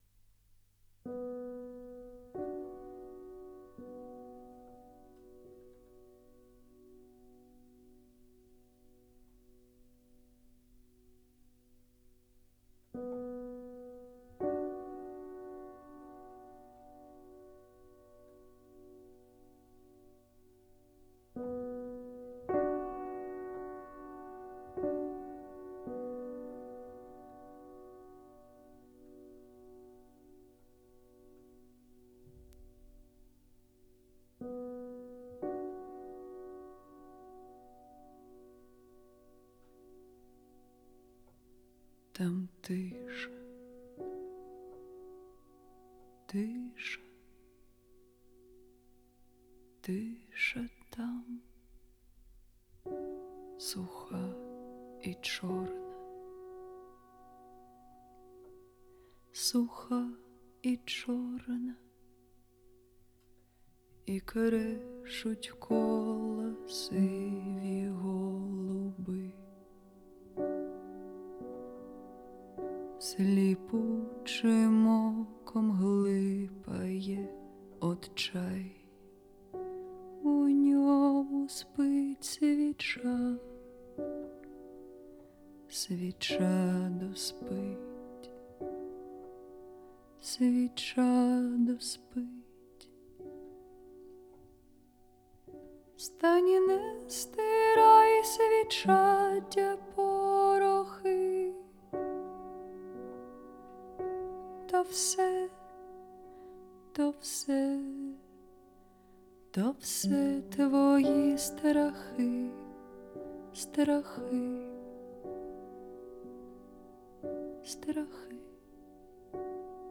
Жанр: Folk